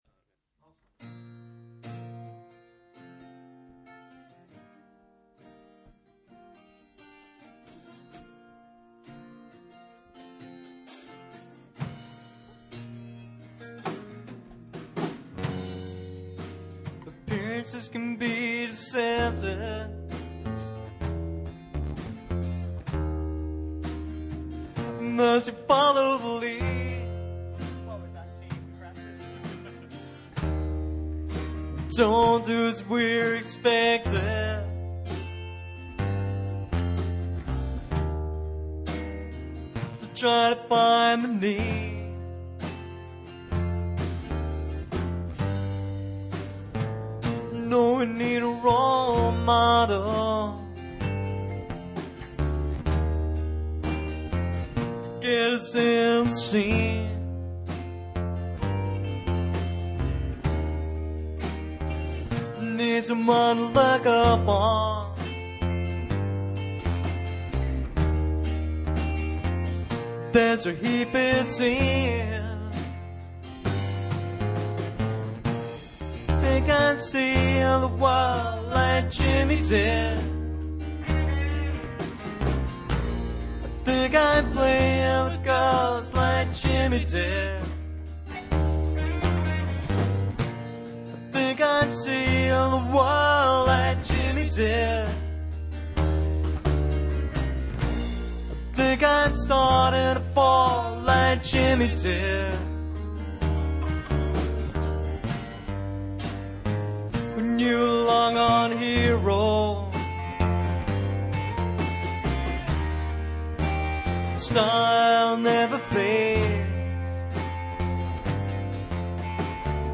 Rehearsal at Orchard
Like Jimmy Did rehearsal.mp3